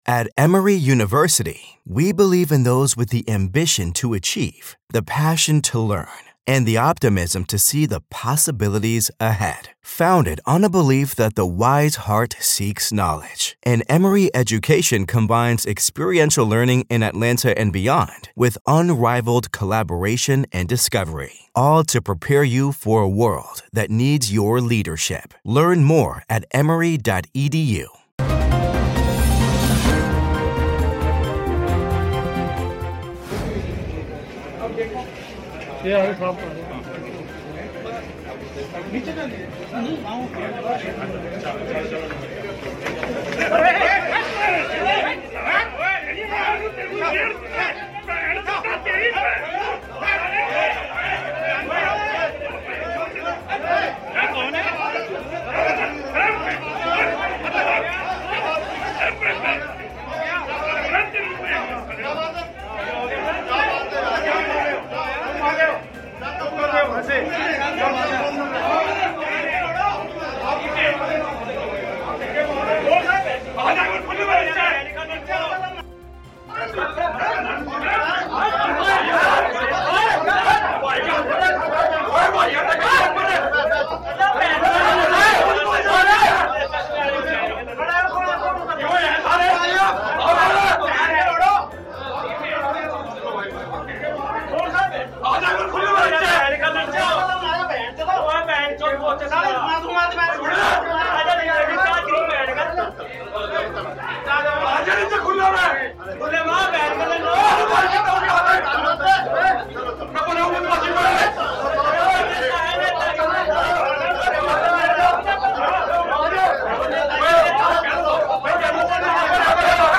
न्यूज़ रिपोर्ट - News Report Hindi / 1984 सिख दंगे : अकाली दल के विधायक ने सिख दंगे के दोषियों पर किया हमला